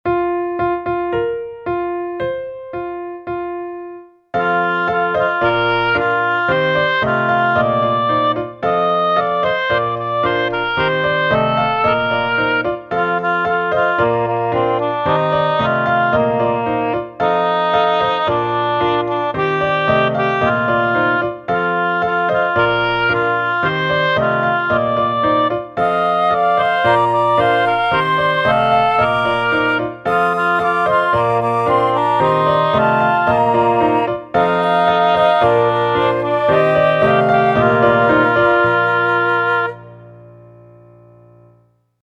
Retrouvez sur cette page les cantiques qui seront chantés lors du culte de la fête du temps de Noël (troisième dimanche de l'Avent), le 15 décembre 2024.
Cantiques